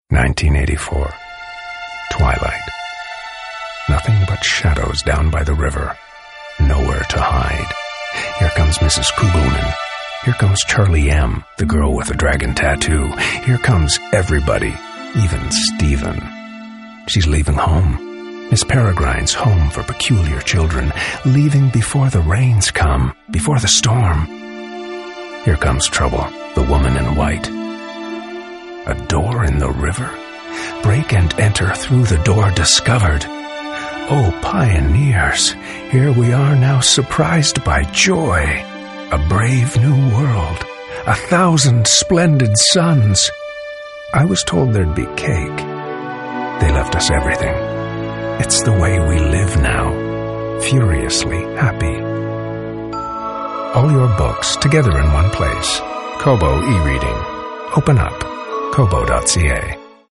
GoldRadio - Campaign